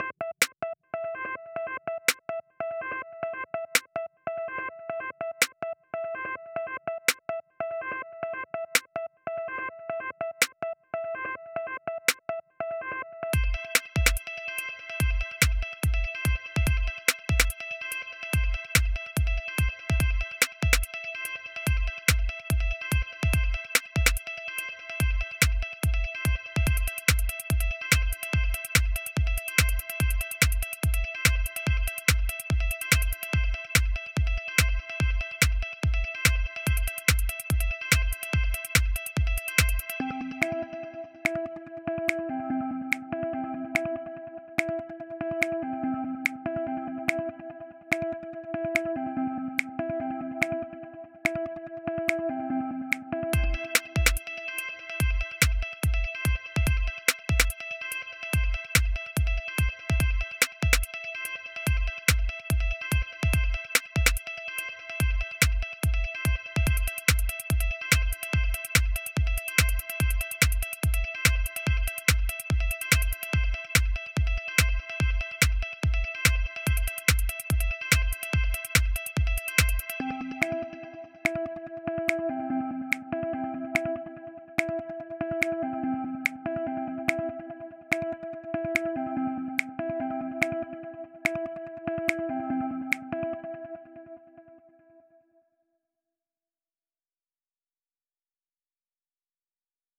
Time – (1:40)　bpm.140